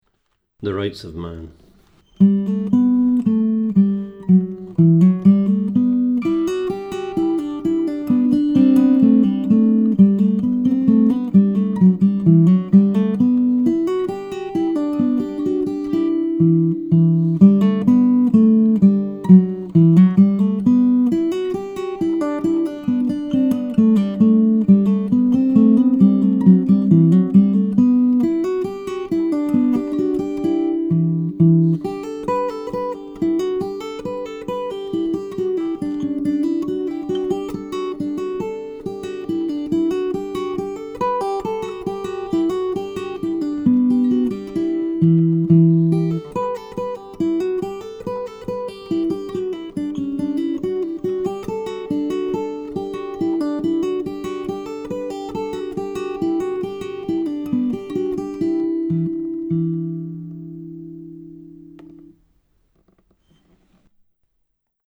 DIGITAL SHEET MUSIC - FINGERPICKING GUITAR SOLO
Celtic session tune, DADGAD tuning